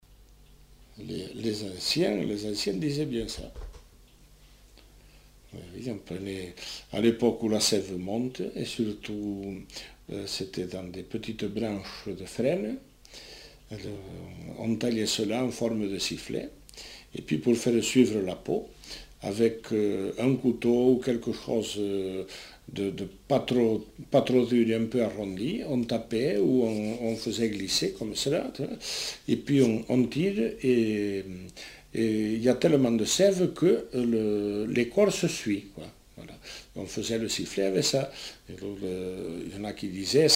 Lieu : Saint-Aventin
Genre : témoignage thématique
Instrument de musique : sifflet végétal